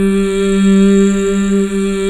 Index of /90_sSampleCDs/Club-50 - Foundations Roland/VOX_xFemale Ooz/VOX_xFm Ooz 1 M